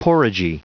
Prononciation du mot porridgy en anglais (fichier audio)